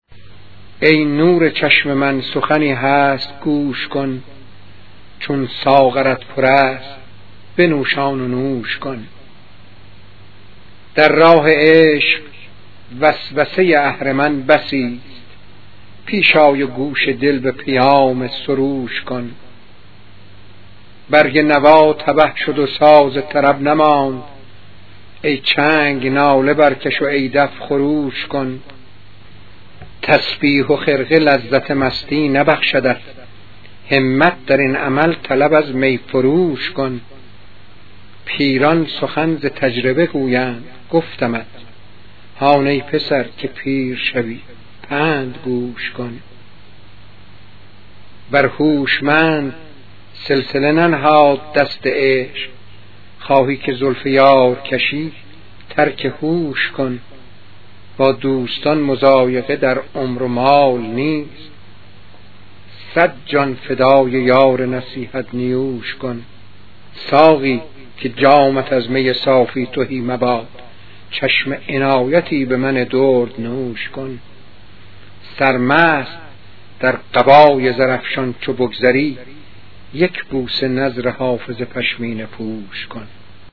🎵 پخش صوتی غزل با صدای موسوی گرمارودی: